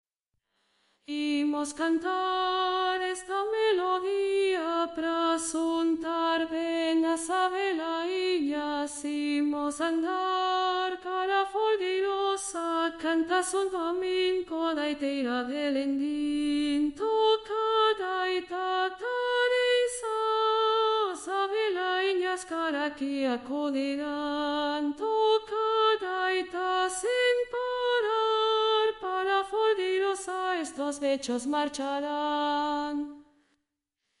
Contralto.mp3